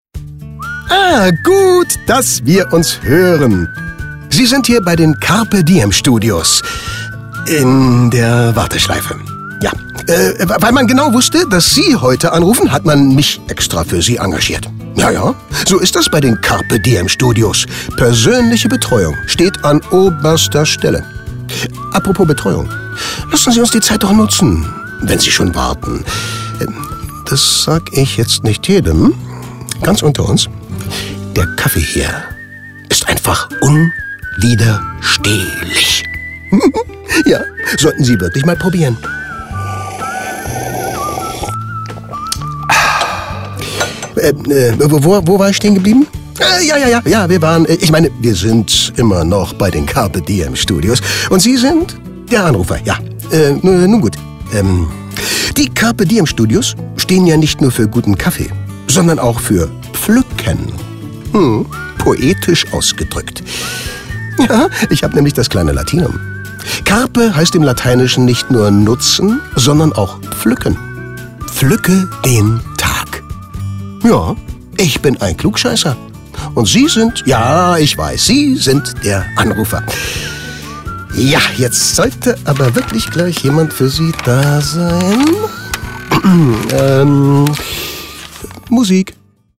versüßen Sie somit die Wartezeit durch erfrischende Sprüche und Effekte.
Telefon-Warteschleife – Carpe Diem Studios I: